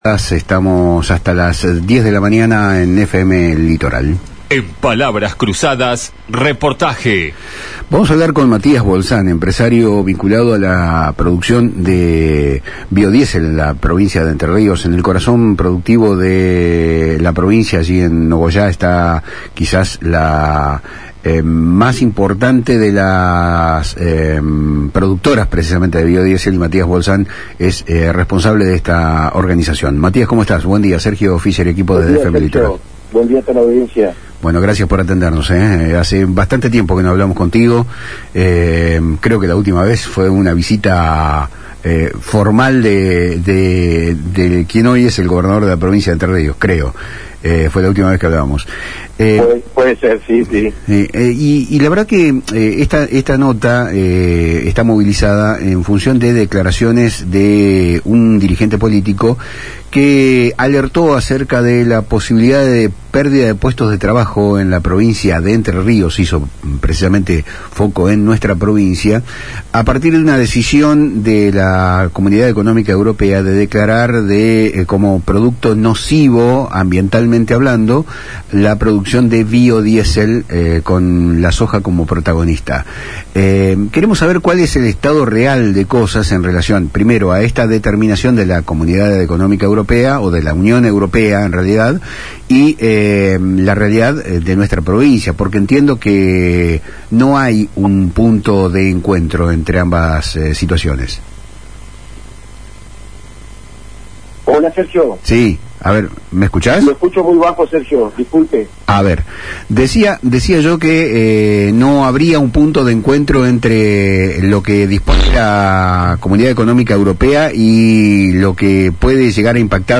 en diálogo con FM Litoral